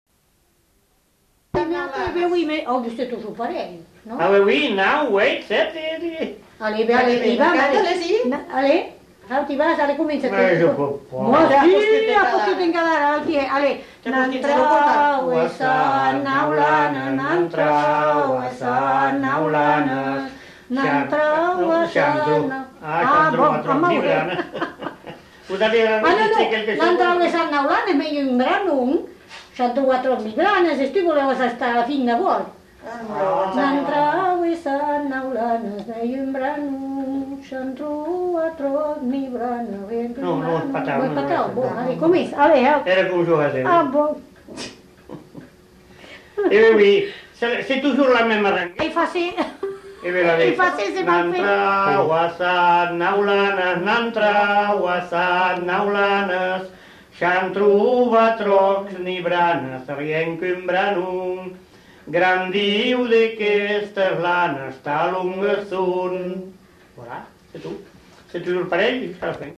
Aire culturelle : Bazadais
Lieu : Cudos
Genre : chant
Effectif : 2
Type de voix : voix d'homme ; voix de femme
Production du son : chanté
Danse : rondeau